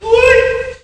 Worms speechbanks
Bungee.wav